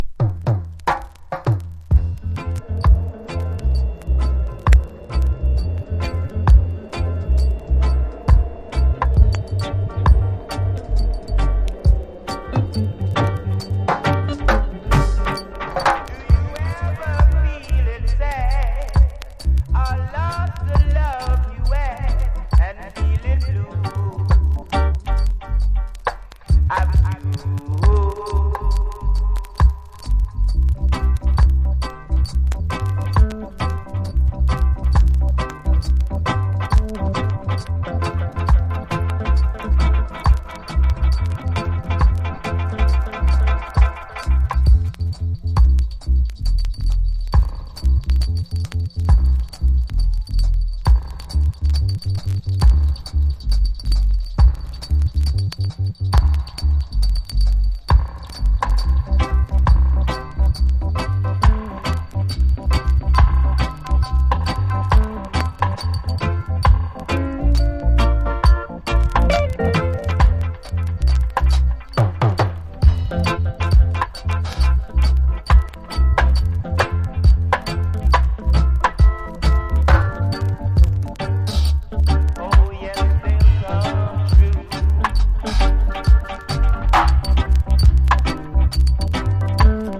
包まれるような演奏がとにかく心地良い、間違いない陣営による良質のダヴ・アルバム。